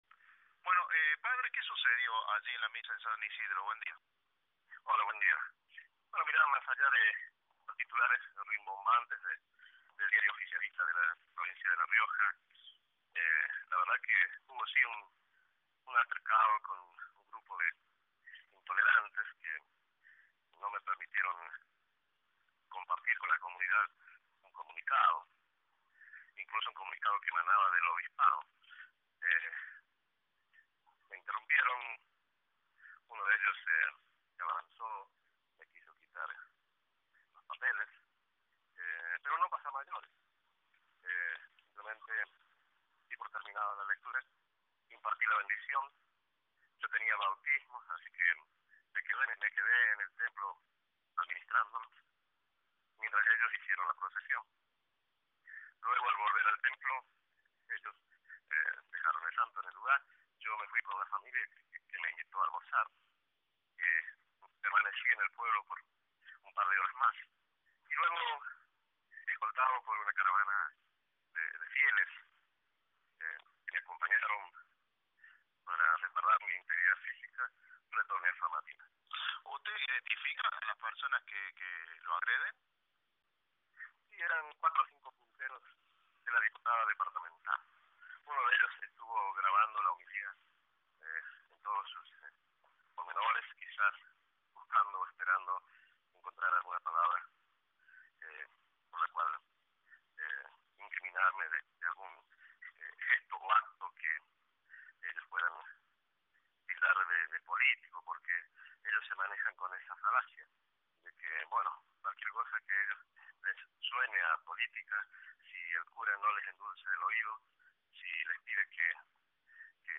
El cura